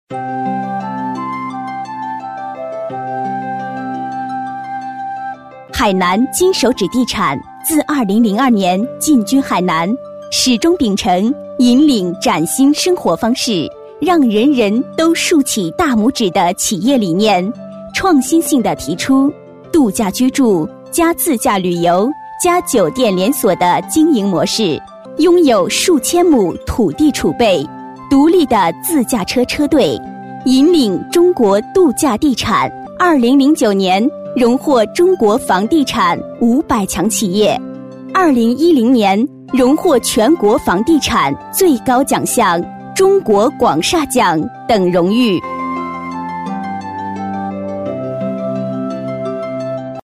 • 房地产广告配音
女24-企业专题【地产公司 稳重 大气】
女24-企业专题【地产公司 稳重 大气】.mp3